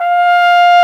Index of /90_sSampleCDs/Roland L-CDX-03 Disk 2/BRS_Cornet/BRS_Cornet 2